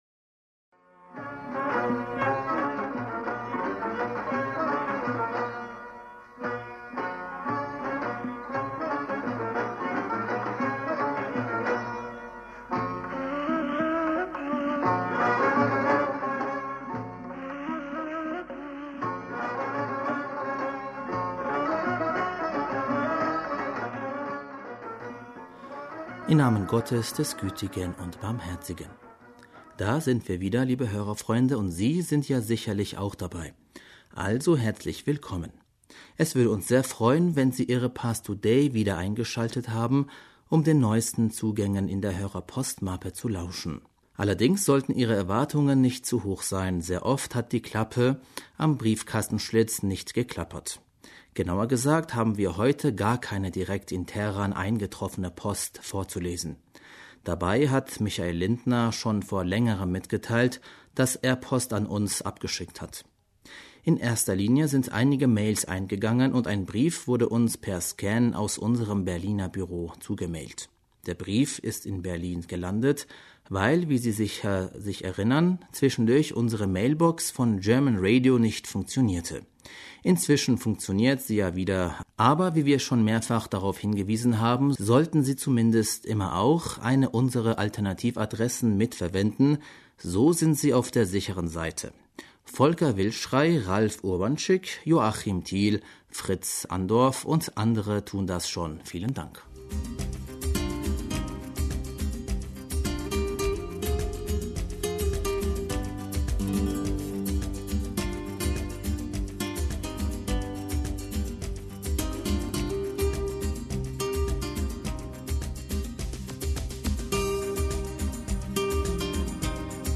Hörerpostsendung